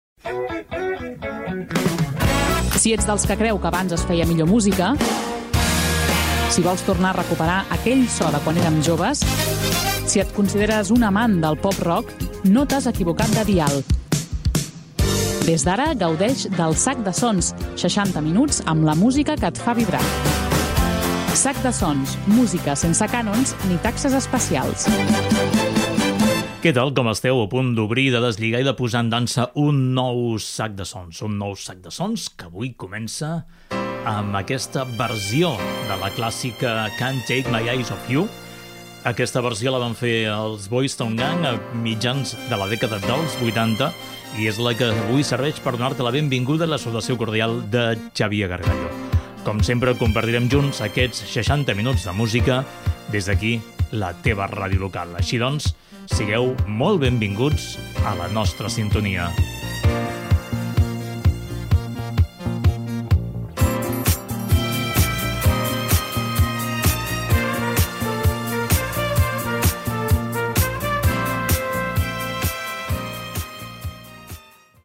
Careta del programa, presentació, tema musical
Musical